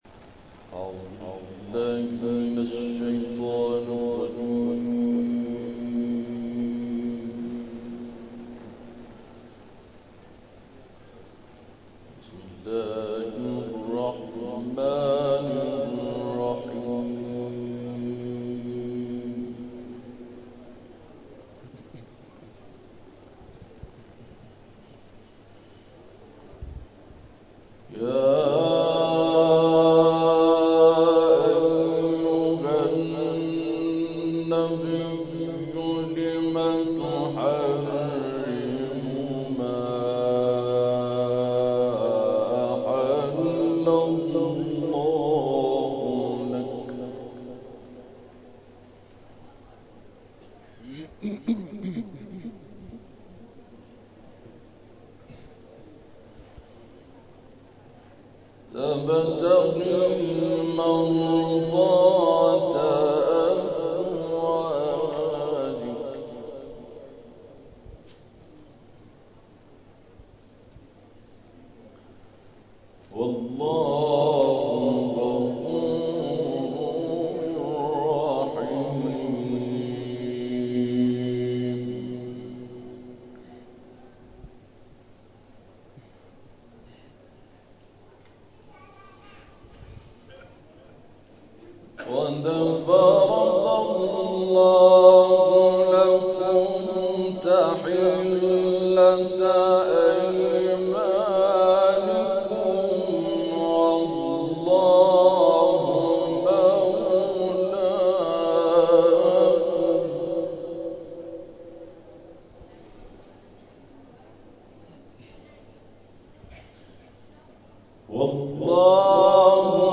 محفل انس با قرآن کریم ویژه عید غدیر خم